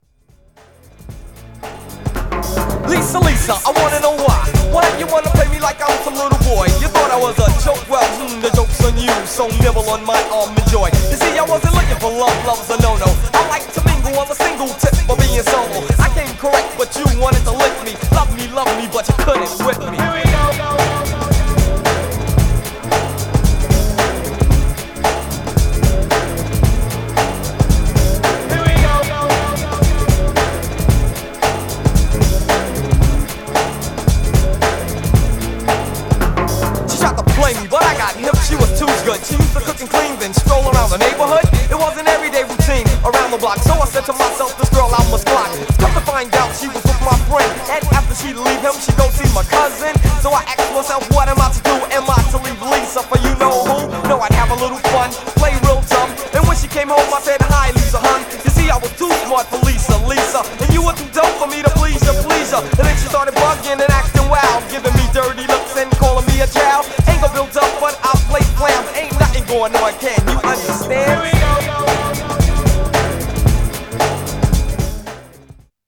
Styl: Hip Hop, Breaks/Breakbeat